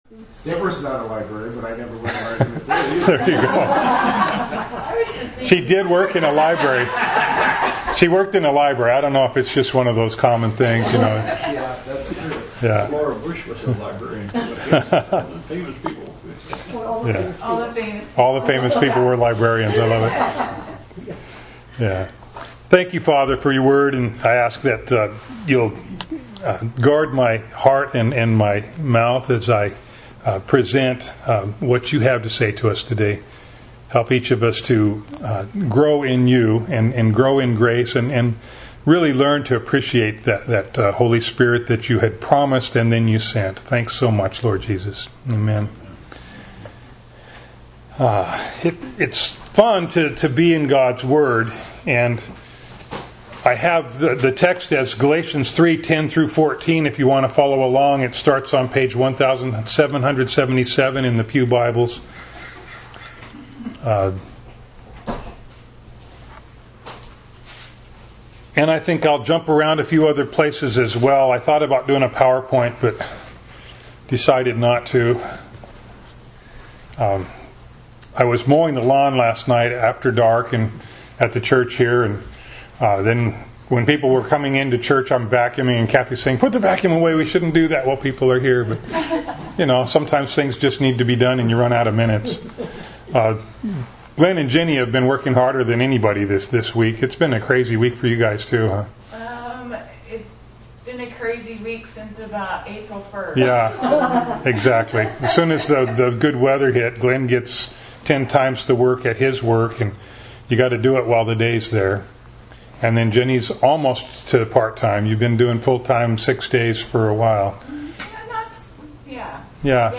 Bible Text: Galatians 3:10-14 | Preacher